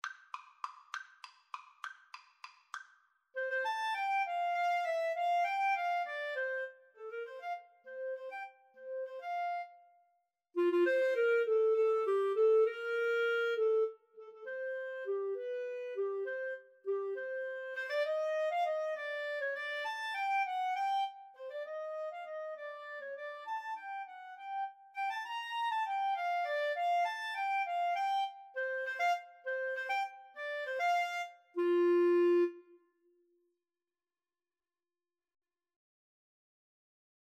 3/8 (View more 3/8 Music)